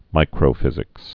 (mīkrō-fĭzĭks)